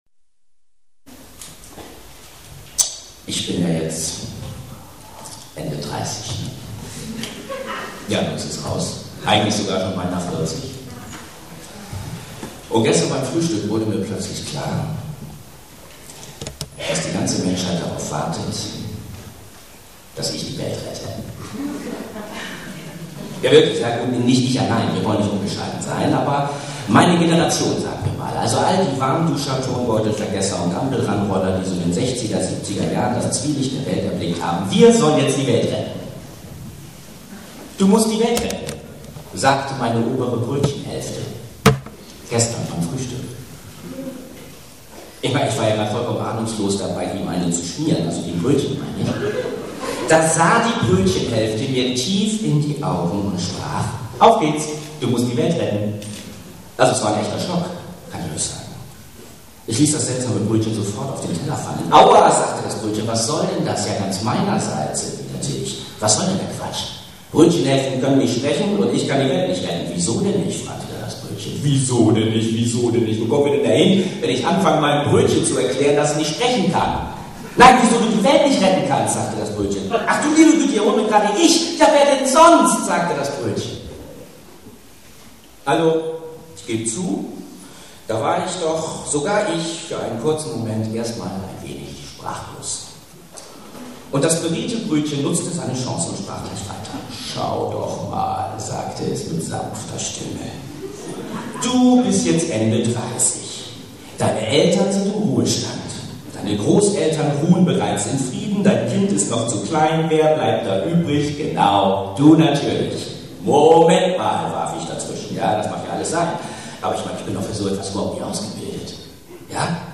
Vorträge